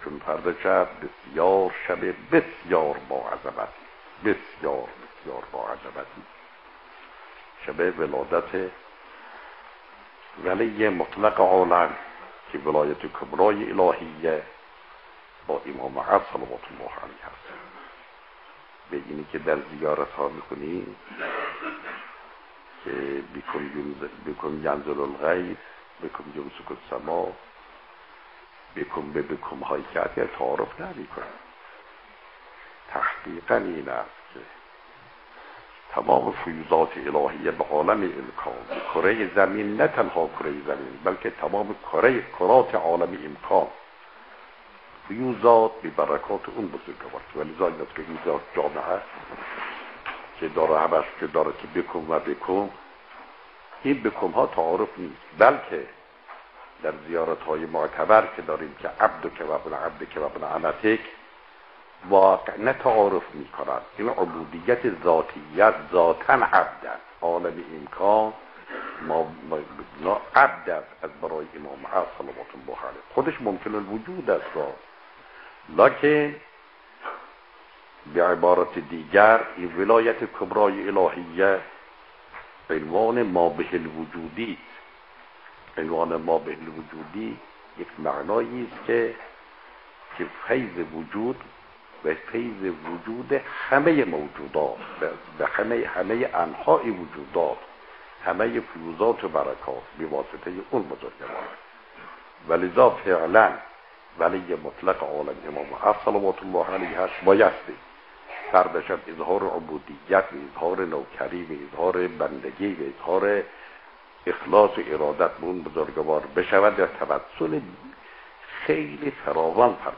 صوت سخنرانی: